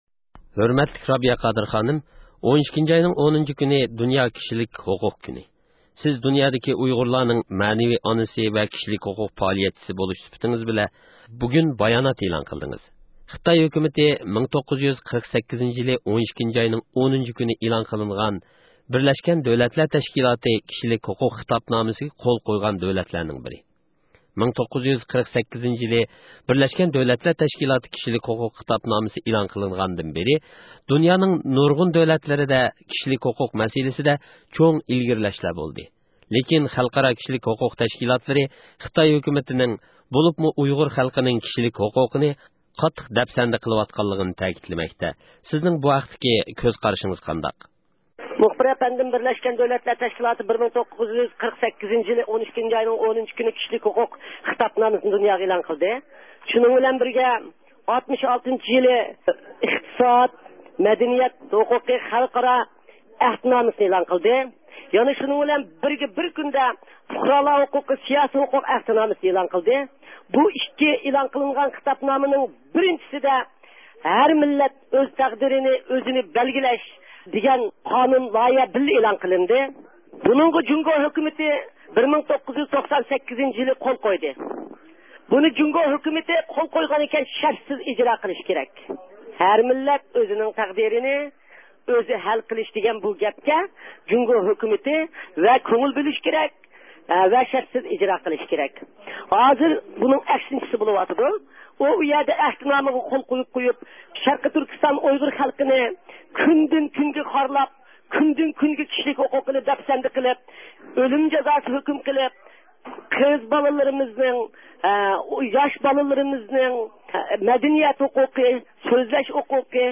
دۇنيا كىشىلىك ھوقۇق كۈنى مۇناسىۋىتى بىلەن رابىيە قادىر خانىمنى زىيارەت – ئۇيغۇر مىللى ھەركىتى